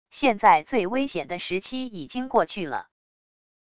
现在最危险的时期已经过去了wav音频生成系统WAV Audio Player